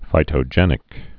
(fītō-jĕnĭk) also phy·tog·e·nous (fī-tŏjə-nəs)